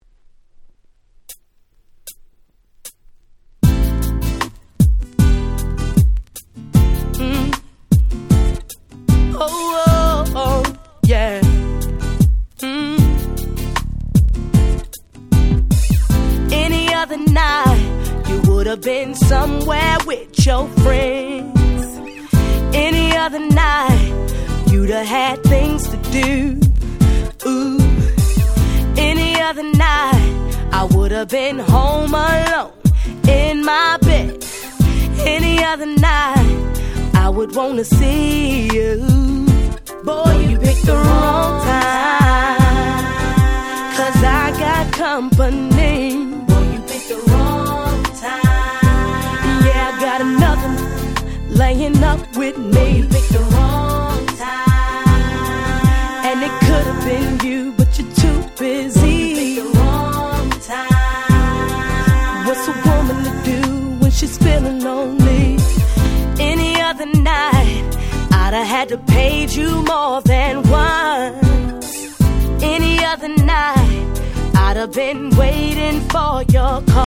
01' Nice R&B !!